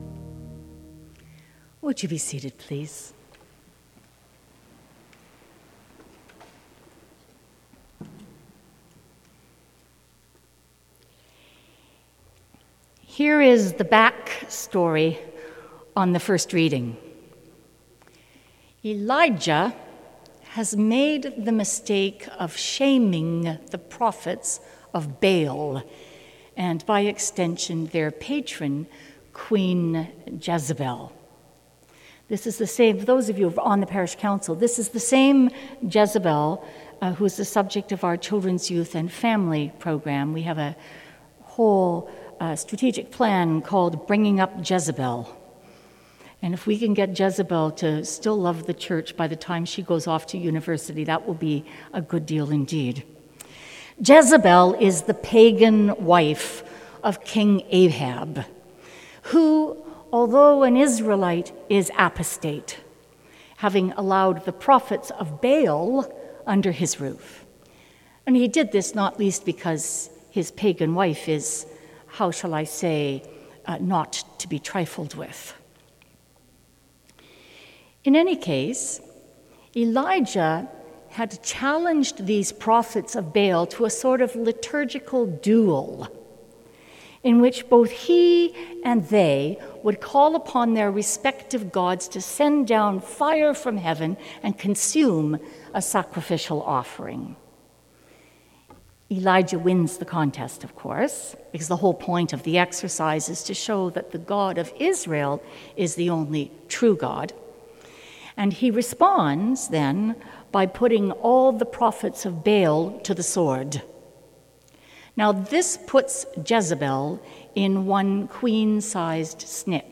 11am Sermon